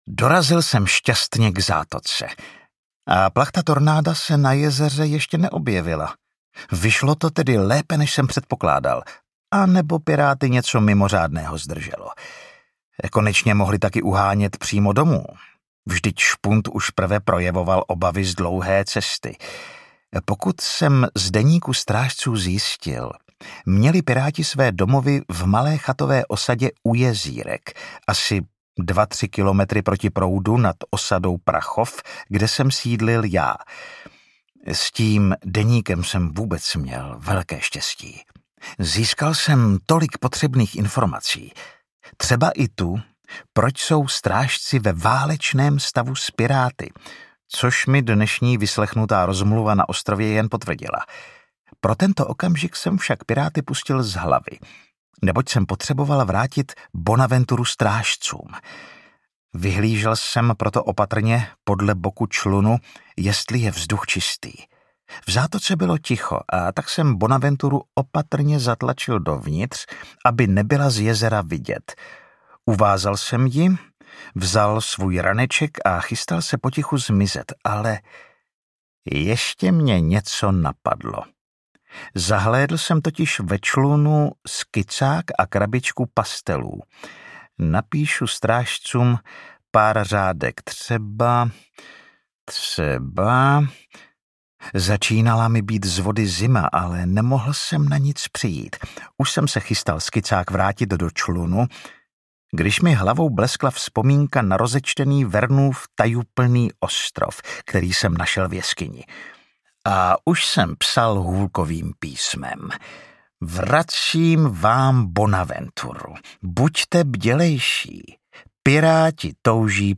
Trampoty s kapitánem audiokniha
Ukázka z knihy
trampoty-s-kapitanem-audiokniha